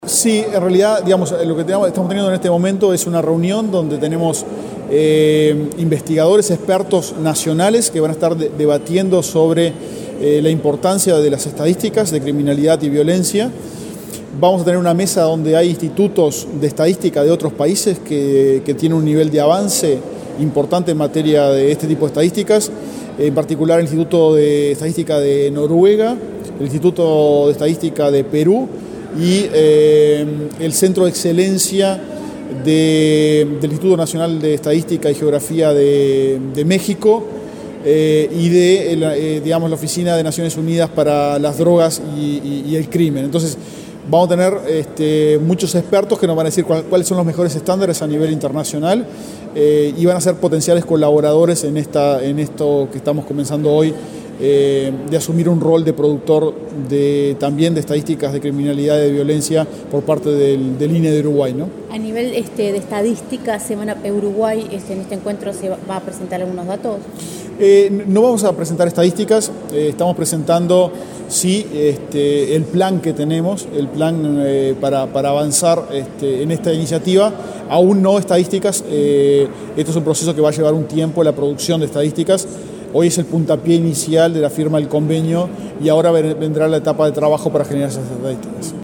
Entrevista al director del INE, Diego Aboal
Este jueves 7 en la Torre Ejecutiva, el director del Instituto Nacional de Estadística (INE), Diego Aboal, dialogó con Comunicación Presidencial